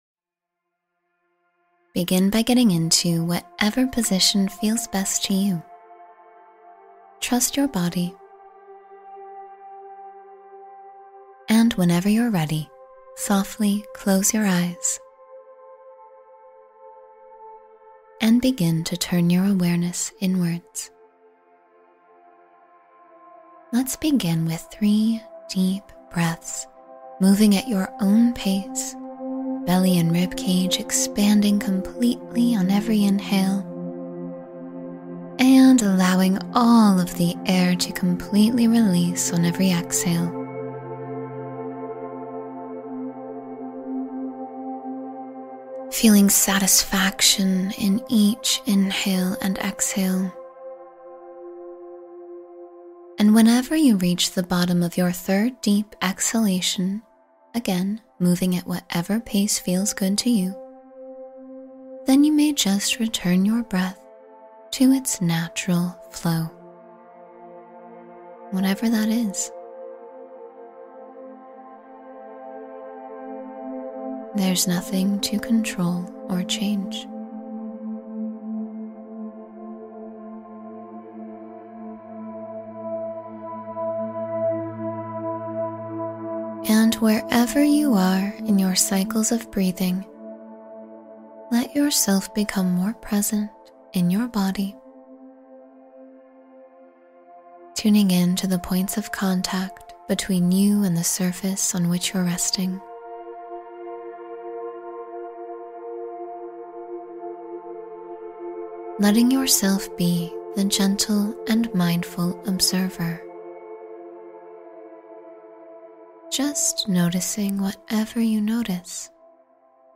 Quiet the Overthinking Mind and Find Peace — Guided Meditation for Relaxation